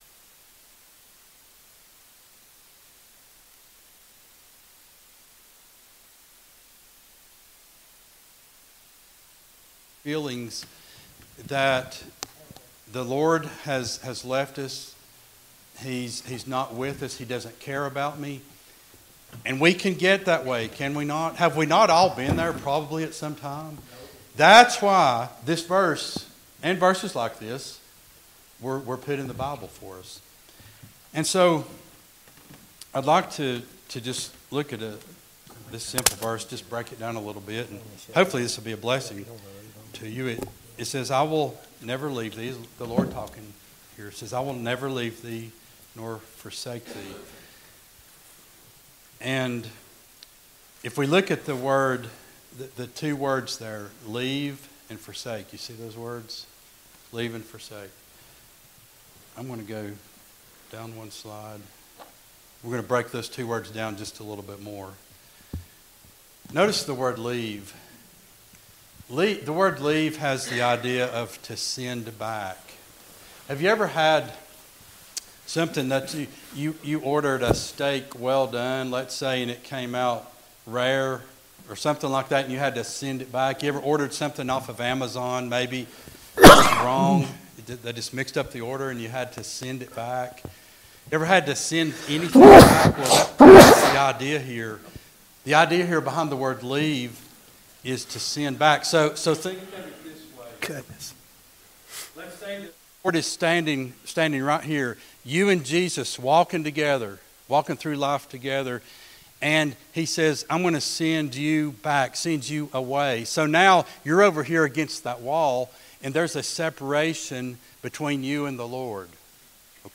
10-29-23 Sunday School Lesson | Buffalo Ridge Baptist Church